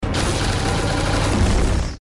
Der Sound der Energiewaffen des Shuttles